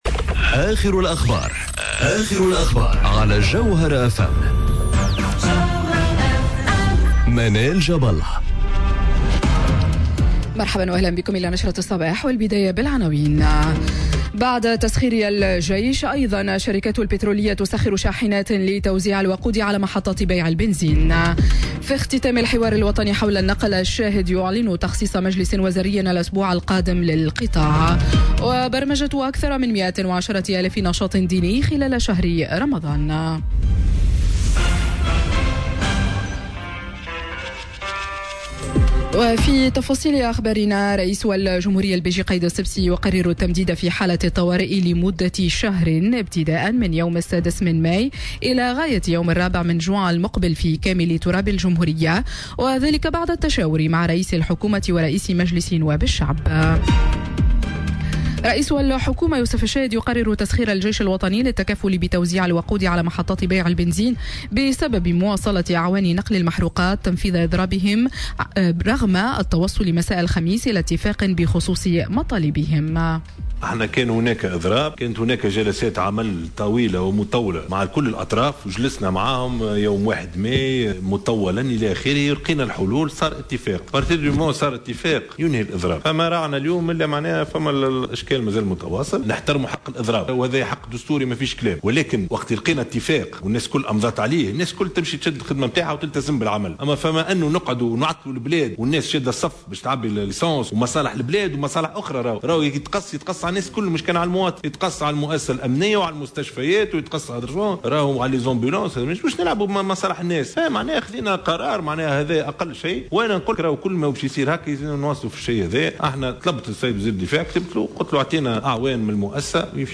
نشرة أخبار السابعة صباحا ليوم السبت 04 ماي 2019